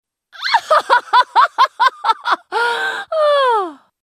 Risada da assassina LeBlanc, a Farsante, de League Of Legends (LoL).
risada-leblanc-lol.mp3